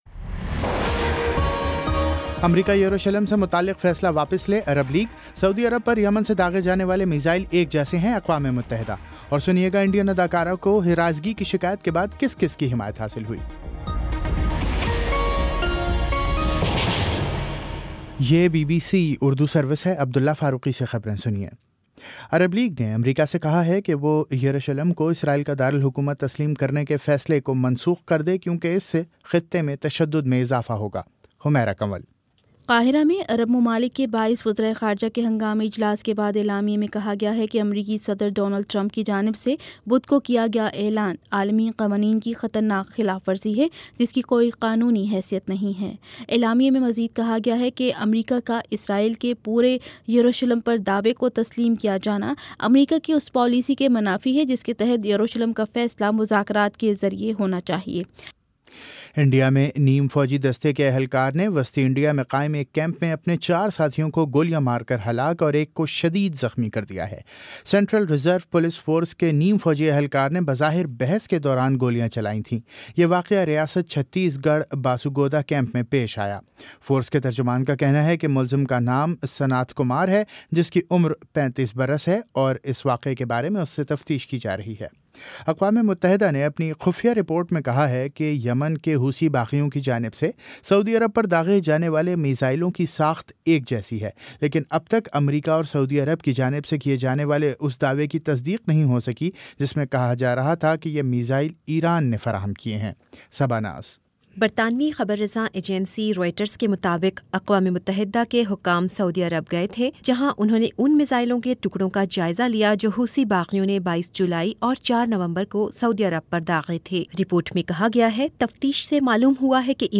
دسمبر 10 : شام پانچ بجے کا نیوز بُلیٹن